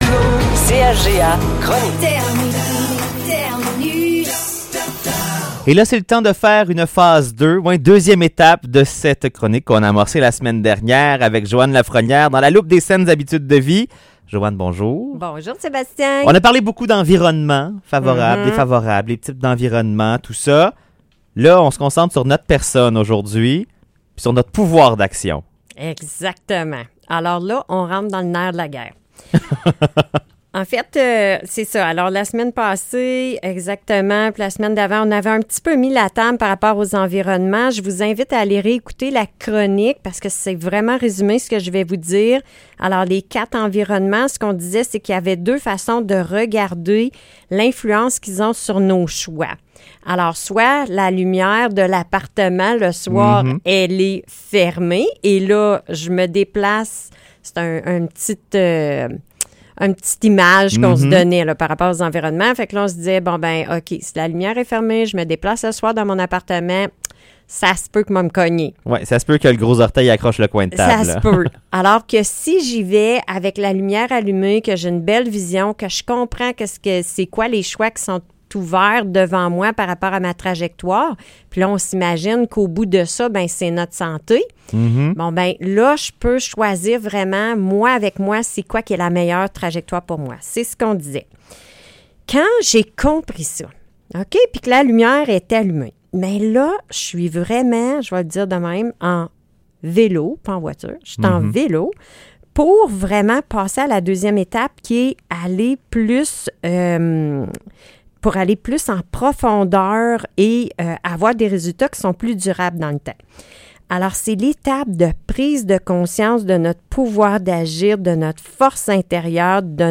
Discussion autour de la question, de nos pensées, de nos émotions et de nos actions.